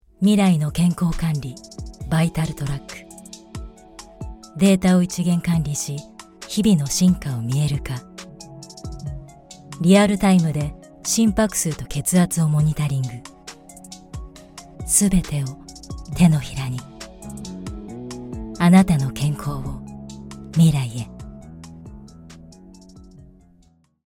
Voice Sample: Commercial 01
We use Neumann microphones, Apogee preamps and ProTools HD digital audio workstations for a warm, clean signal path.